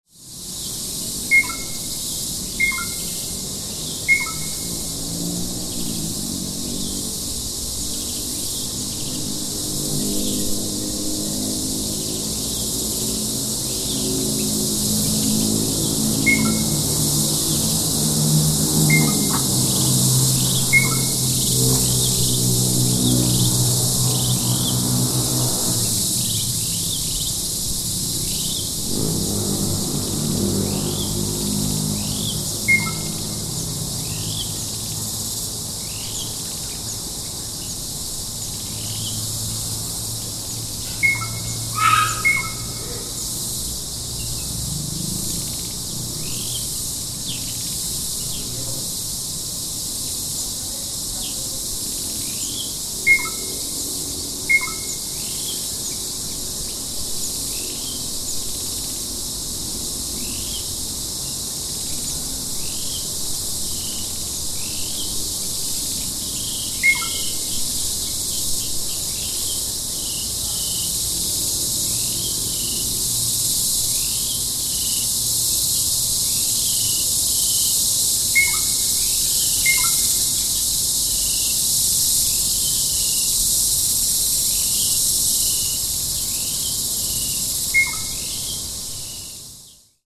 Birdsong in the Thai jungle
In the jungles of Thailand, in the center of Koh Samui, we hear not only the singing of a bird, but also other sounds: some kind of transport, other animals, but the singing of a bird remains unchanged against the background of all other sounds.